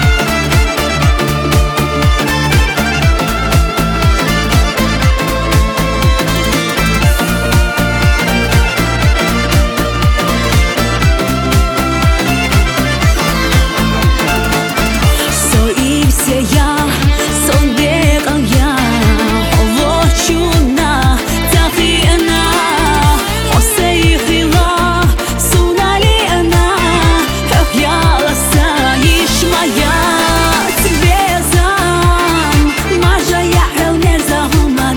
Жанр: Поп музыка / Альтернатива / Русский поп / Русские
Indie Pop, Alternative